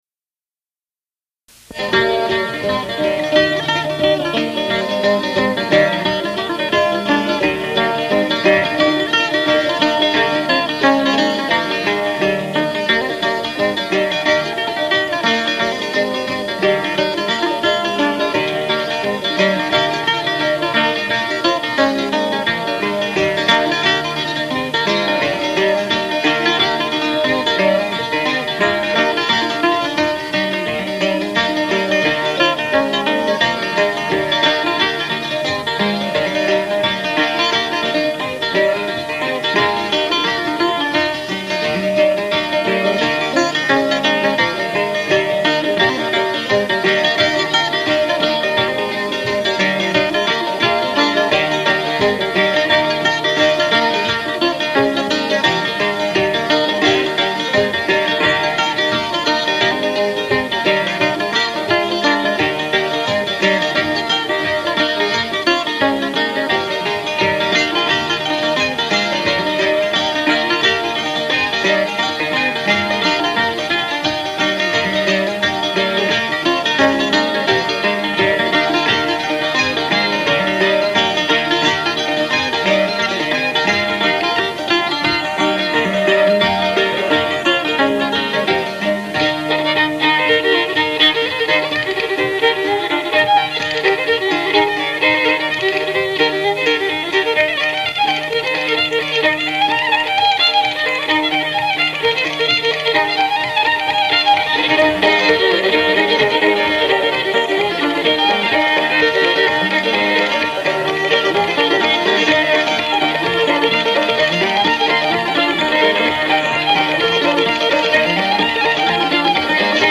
h'pipe
reels